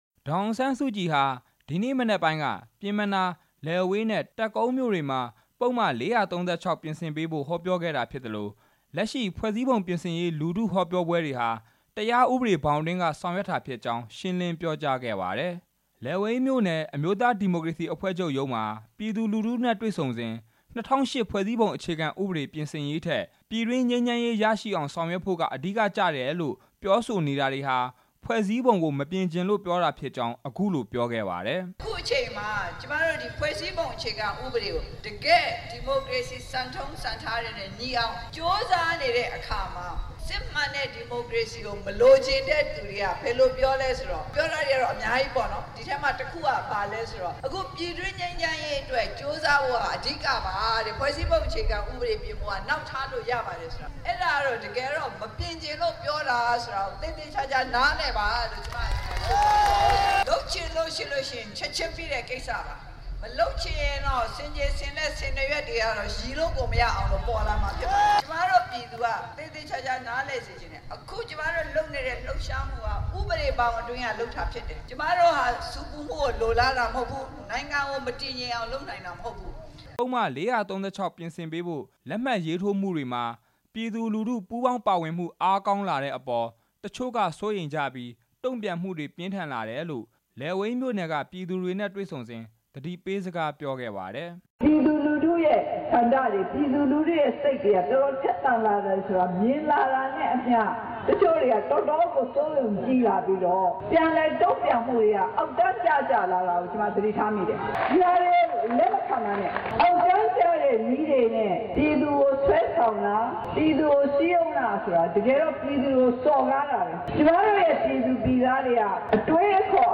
နေပြည်တော် ပျဉ်းမနားမြို့နယ် အမျိုးသားဒီမိုကရေစီ အဖွဲ့ချုပ်ရုံးမှာ ကျင်းပတဲ့ ပုဒ်မ ၄၃၆ ပြင်ဆင်ရေး လက်မှတ်ရေးထိုးပွဲမှာ အခုလိုပြောကြားခဲ့တာ ဖြစ် ပါတယ်။